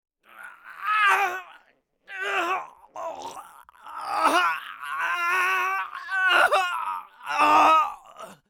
В подборке – резкие вскрики, предсмертные стоны и другие жуткие эффекты длиной от 1 до 16 секунд.
Человек кричит от боли перед гибелью, так как его ранили